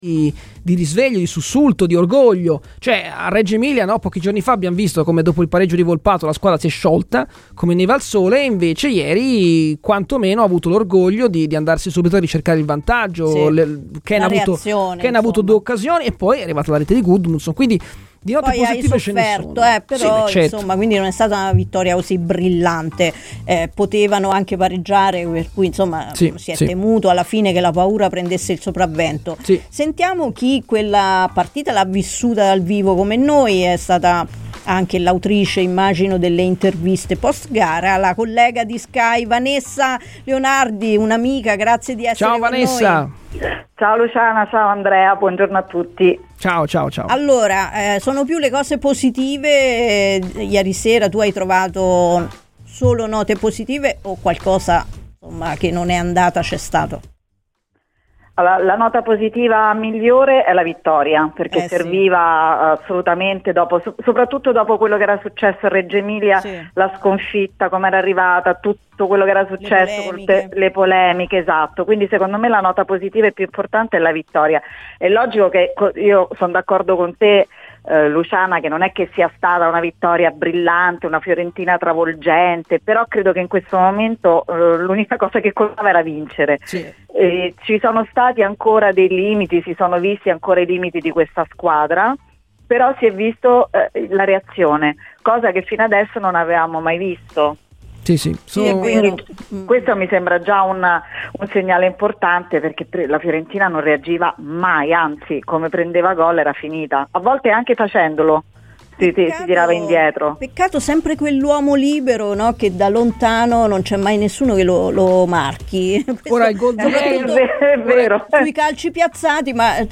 Ascolta l'intervista completa su Radio FirenzaViola